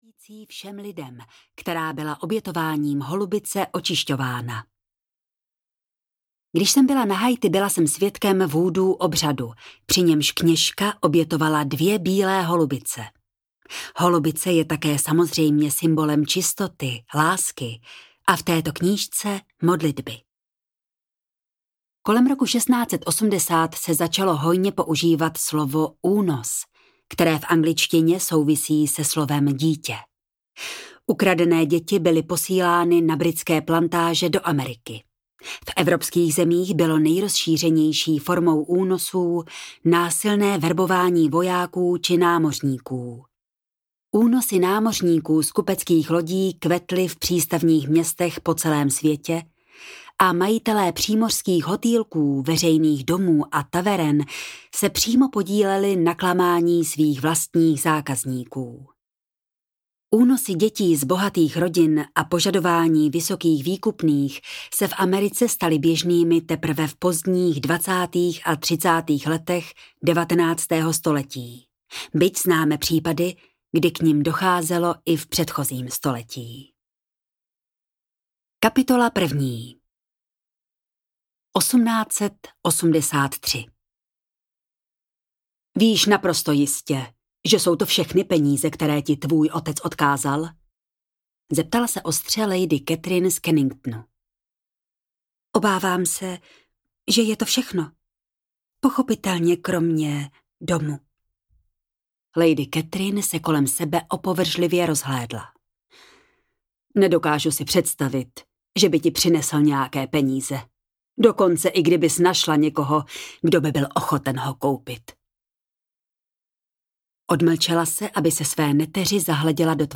Holubice lásky audiokniha
Ukázka z knihy